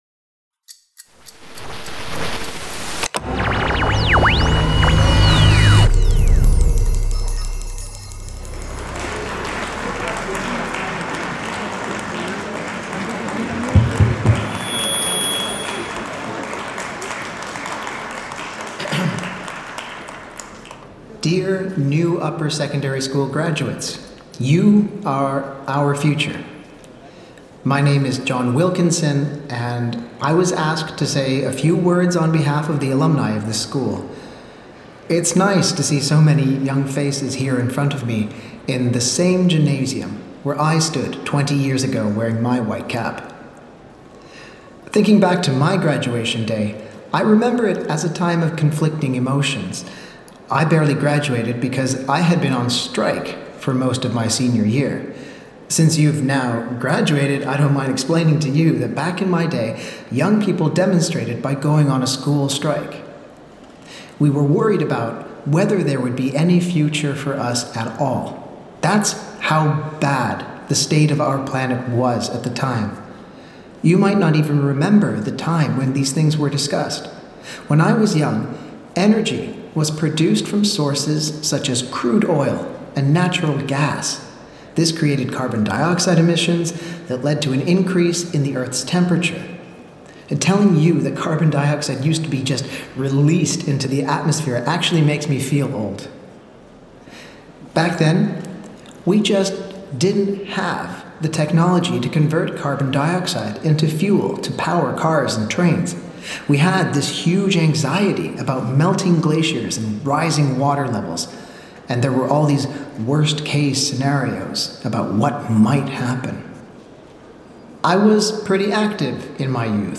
These audio drama pieces represent exaggerated versions of prevailing views of the future.
3-technology-saves-graduation-speech-v2.mp3